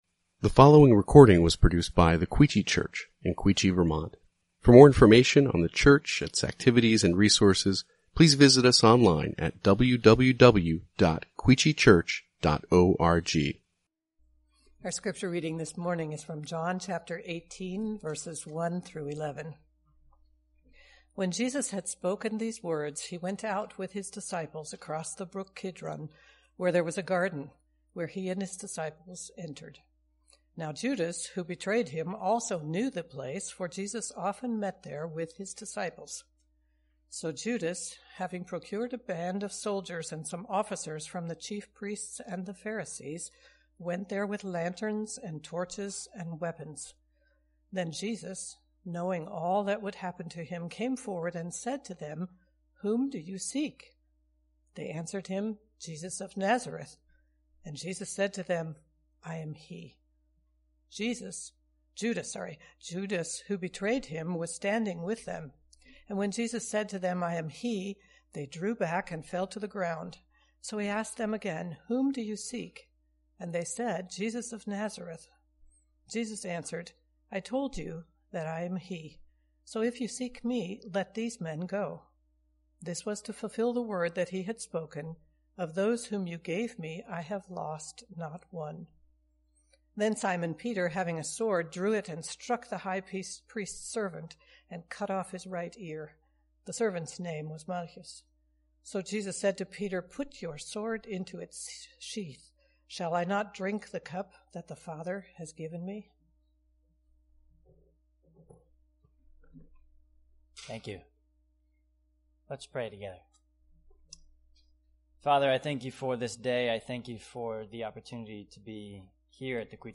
Quechee Church | Sermon Categories